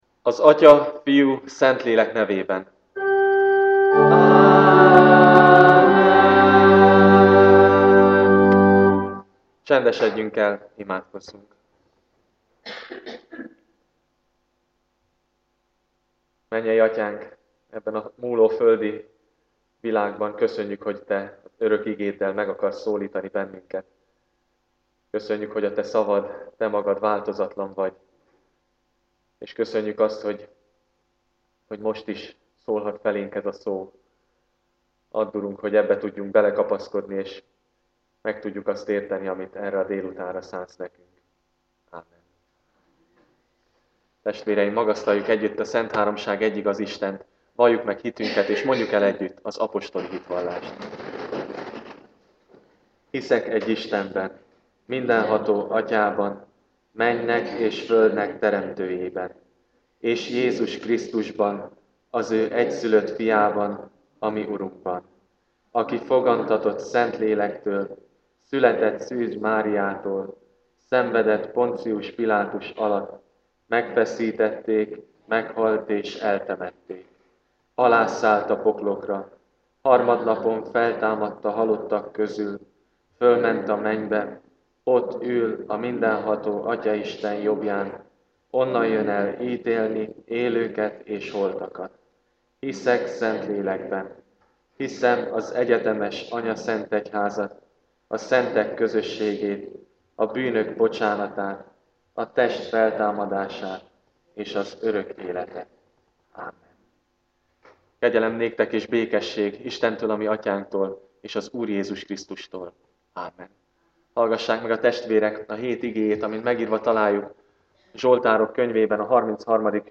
Igehirdetések